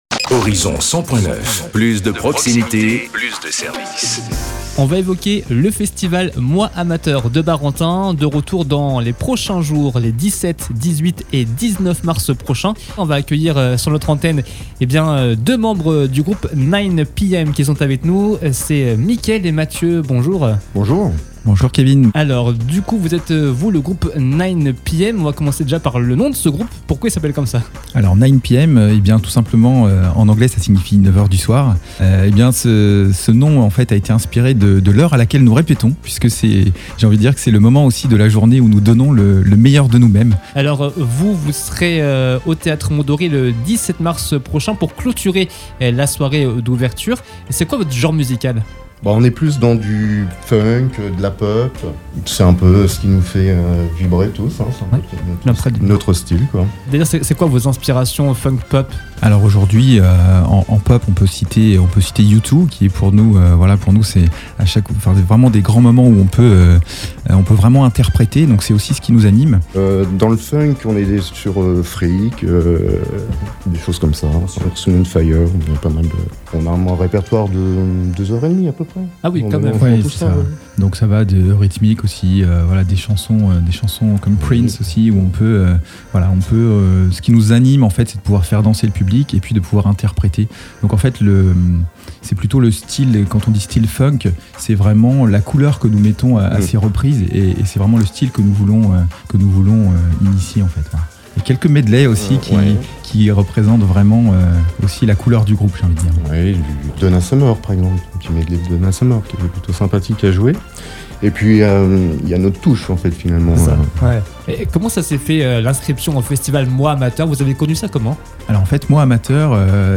Interview du groupe 9PM :